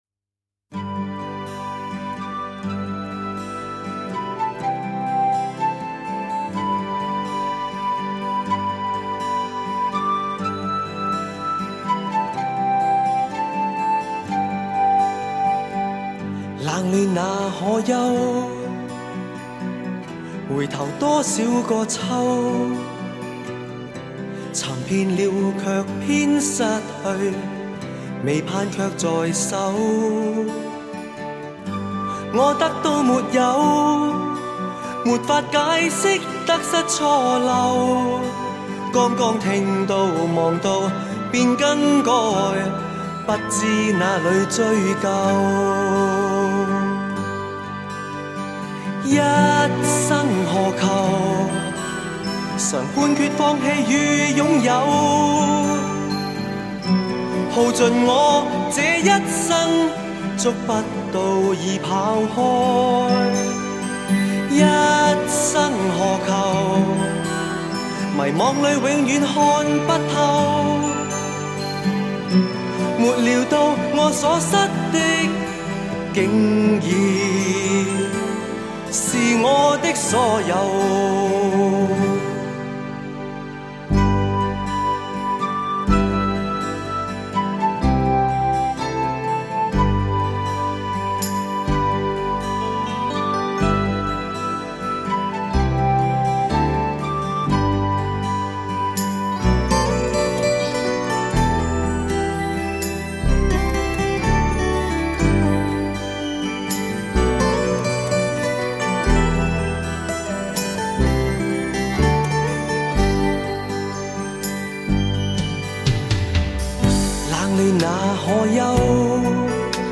HI-FI顶级人声发烧天碟